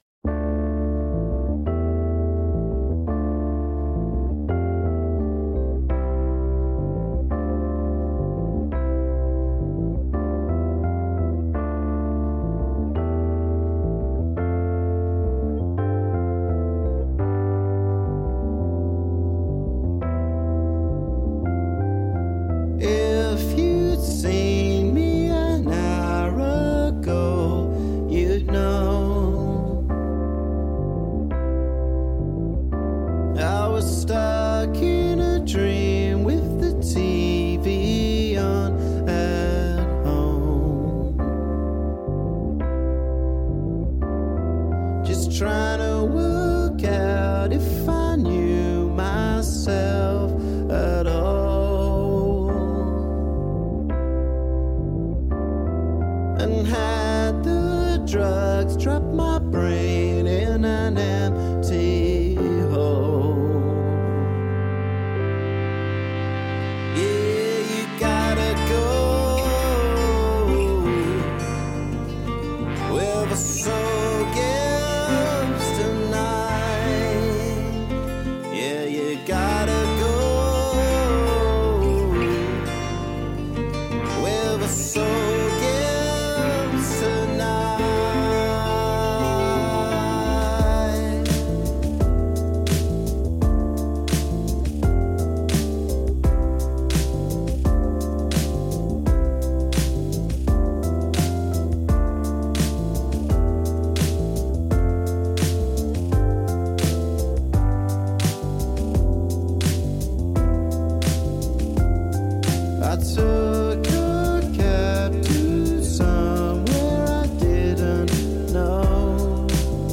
un'intervista in cui si parla anche di French Touch, Techno e politica.